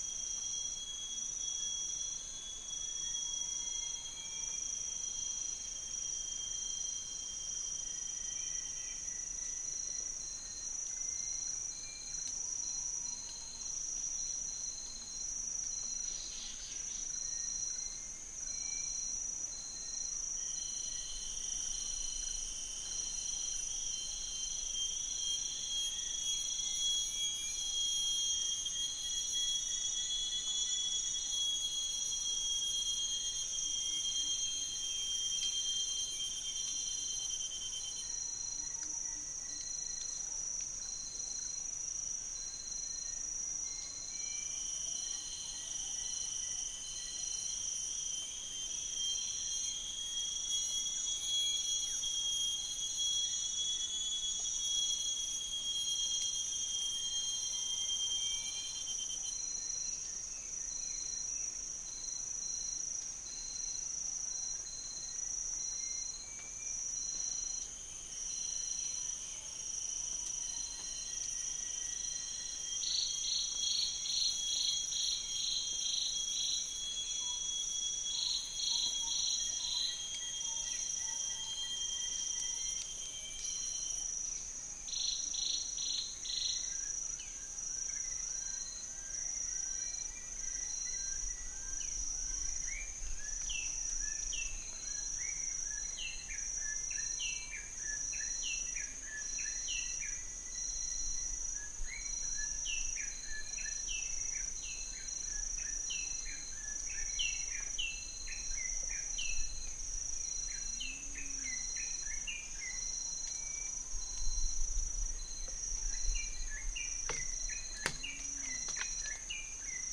Centropus sinensis
Ducula aenea
Macronus ptilosus
Gracula religiosa
Caprimulgus macrurus